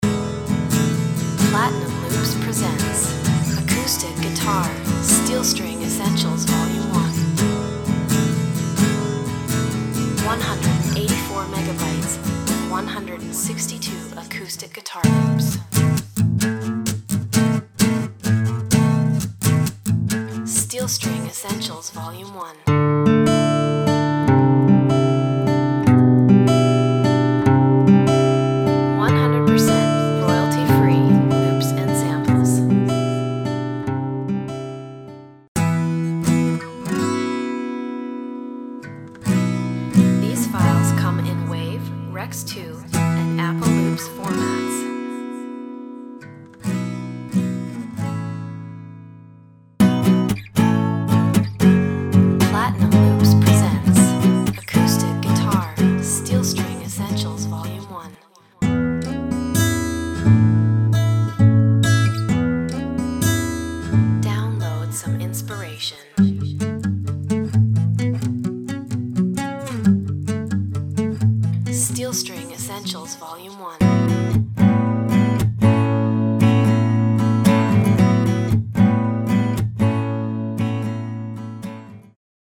There's nothing more pure than the raw sound of the acoustic guitar and these sample packs give you some great song ideas as soon as you load them into Garageband.
Recorded acoustically with the legendary AKG Solidtube condenser mic, these loops offer the ultimate in sound quality as well as playing perfection.
steel_string_essentials_v1.mp3